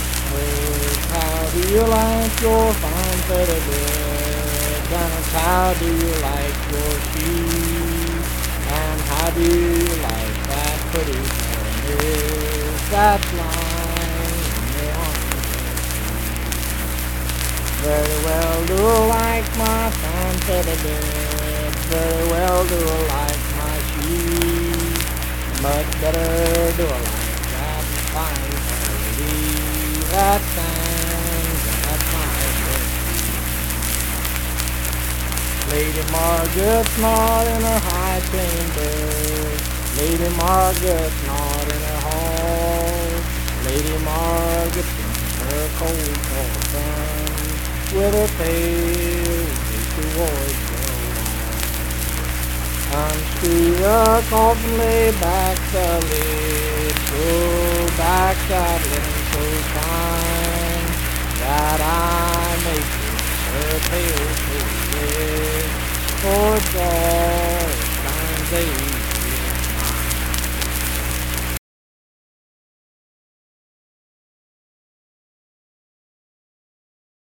Unaccompanied vocal music
Verse-refrain 4(4).
Voice (sung)
Saint Marys (W. Va.), Pleasants County (W. Va.)